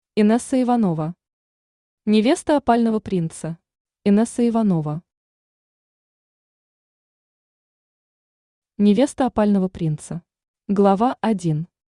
Аудиокнига Невеста опального принца | Библиотека аудиокниг
Aудиокнига Невеста опального принца Автор Инесса Иванова Читает аудиокнигу Авточтец ЛитРес.